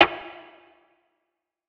TS Perc_3.wav